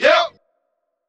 Vox 2 [ yup ].wav